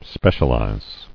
[spe·cial·ize]